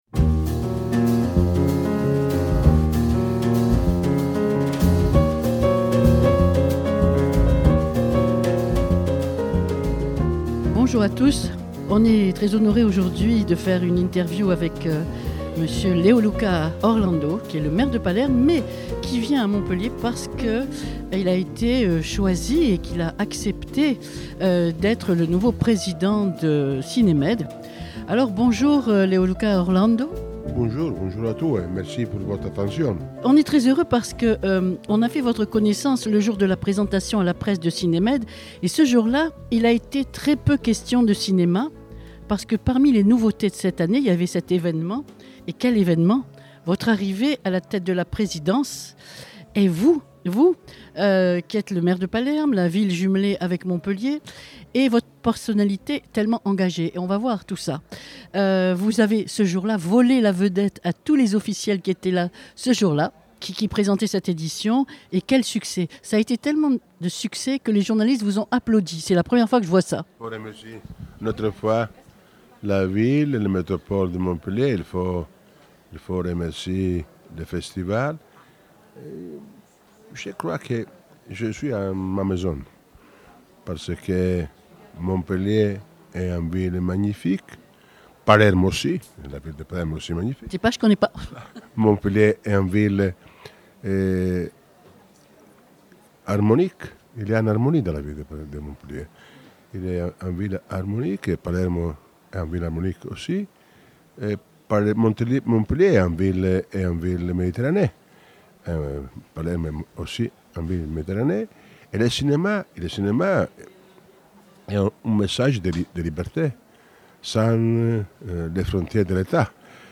INTERVIEW - MAIRE DE PALERME 2610+
INTERVIEW-MAIRE-DE-PALERME-2610-1.mp3